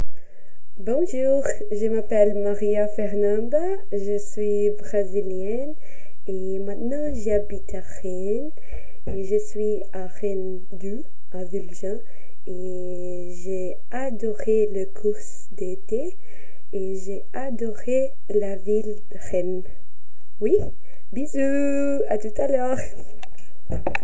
Cabine de témoignages